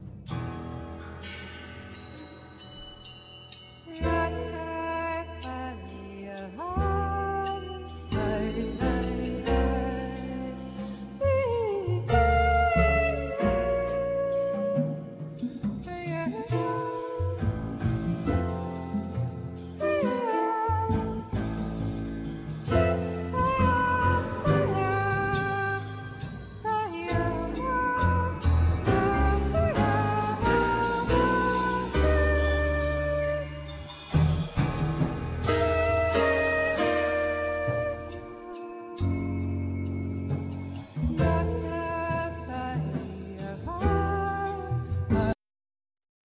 Trumpet
Piano & Keyboard
Basses
Percussion,Drums,Voice
Drums